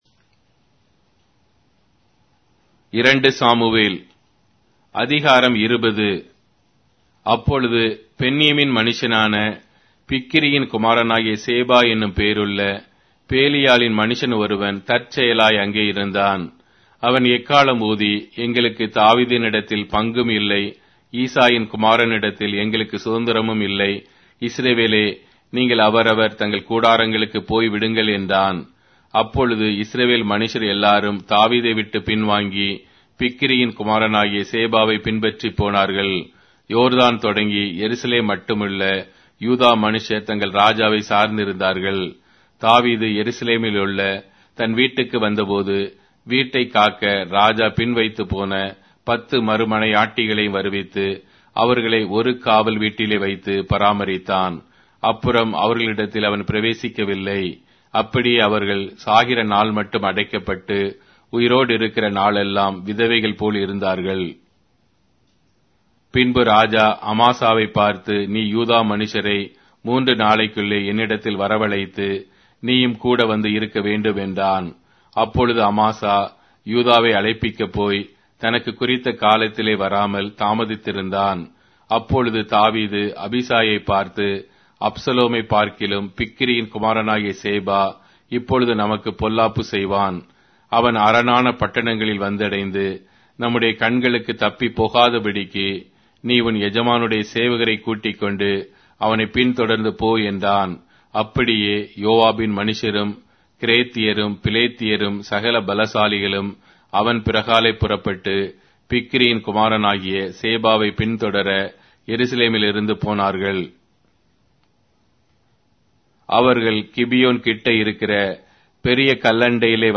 Tamil Audio Bible - 2-Samuel 9 in Tov bible version